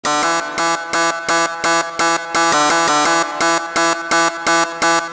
громкие
жесткие
без слов
цикличный
резкие